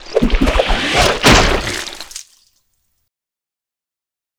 dong2.wav